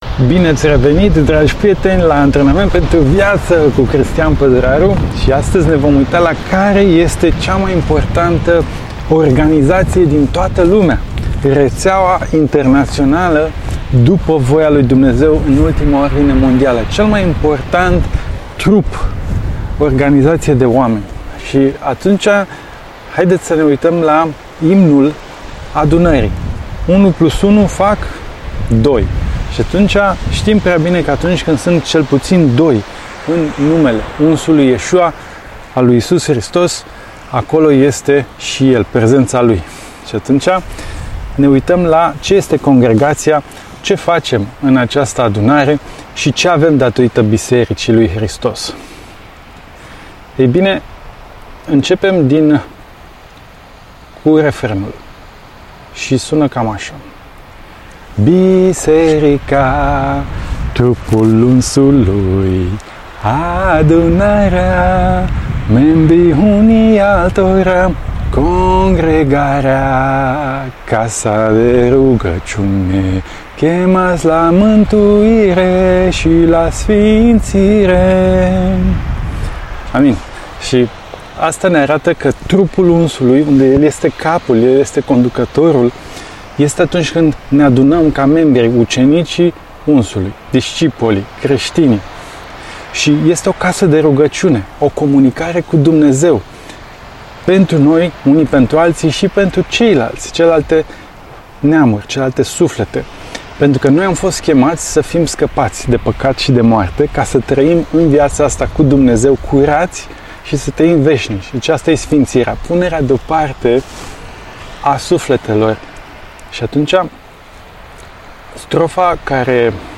Citirea Bibliei Audio Sapt 43 Daniel Explicat Judecata lui Dumnezeu